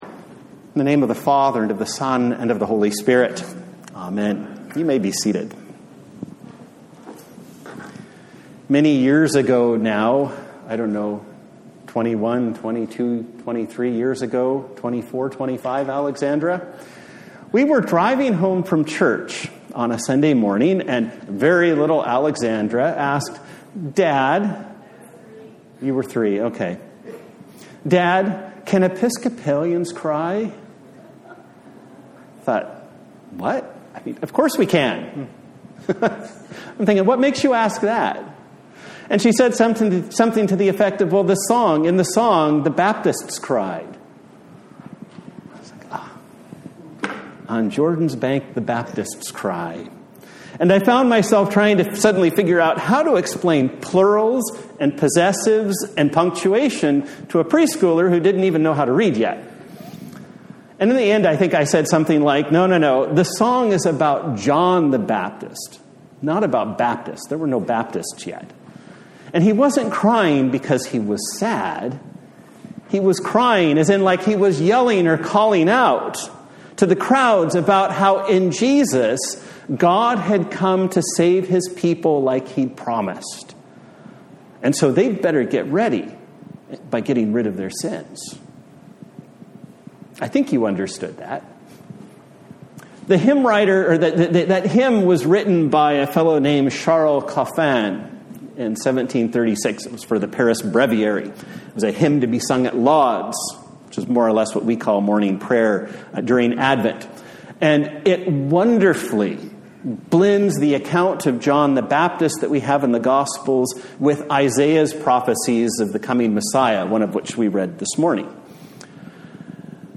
A Sermon for the Third Sunday in Advent
Service Type: Sunday Morning